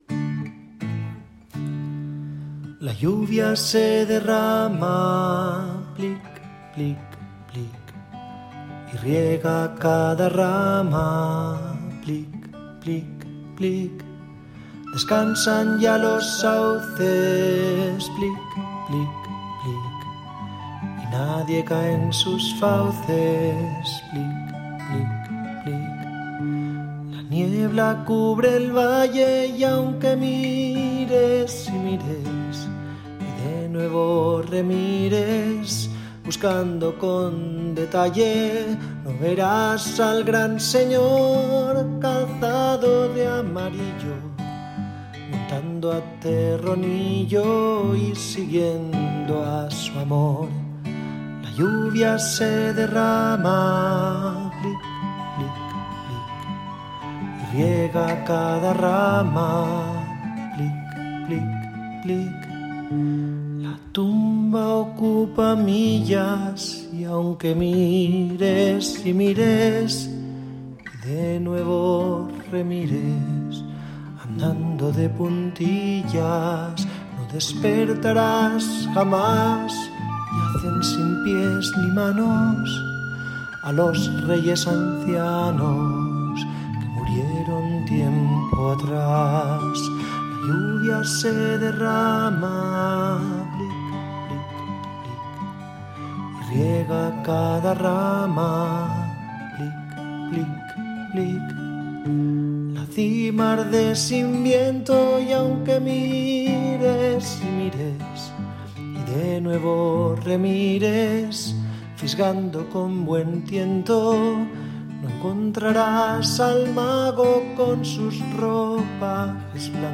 Canción Hobbit